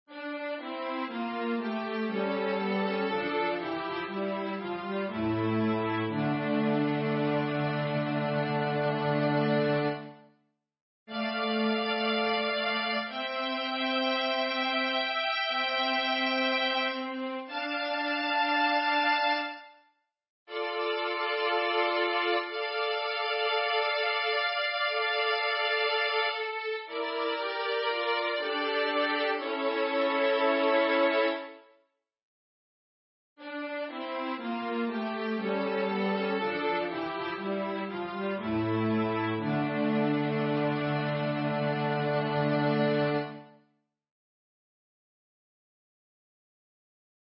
Title: ad Matrem Desertorum Lyricist: Carles Salvadorcreate page et al. Number of voices: 1v Voicing: S Genre: Sacred, Sacred song
Language: Catalan Instruments: Organ